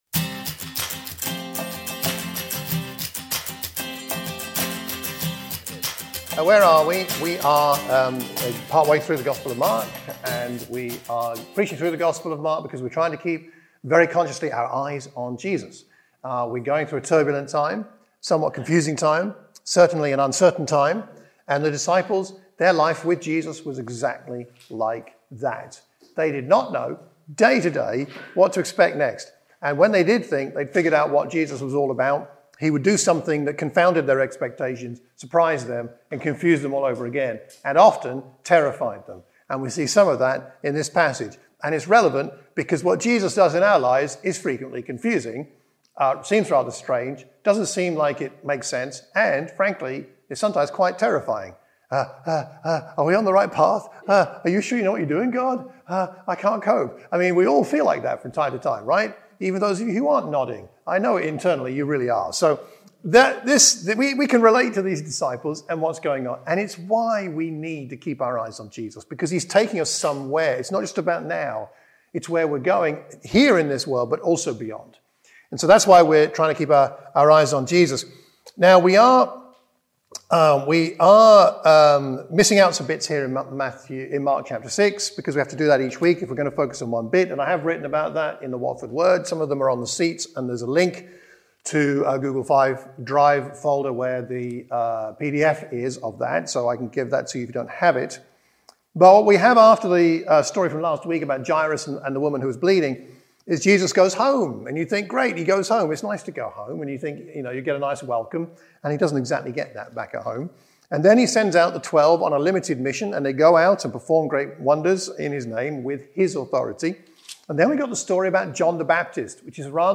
In this sermon for the Watford Church of Christ we explore the way in which the identity of Jesus developed for his disciples.